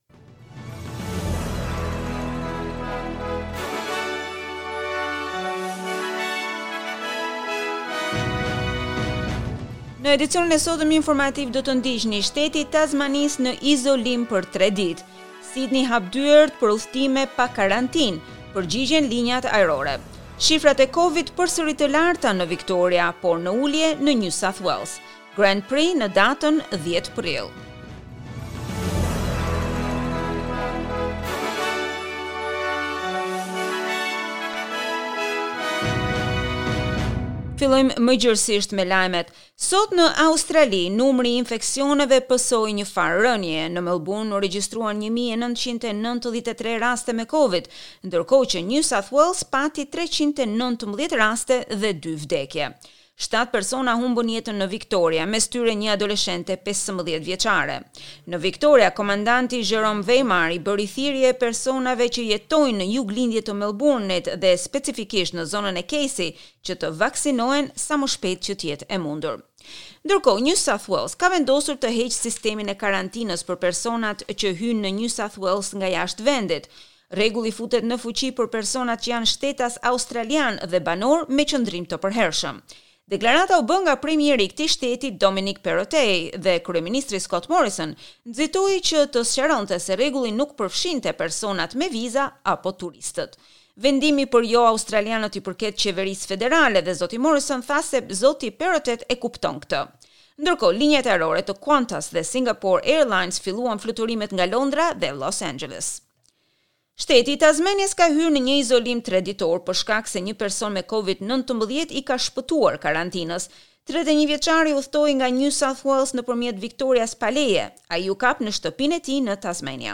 SBS News Bulletin in Albanian - 16 October 2021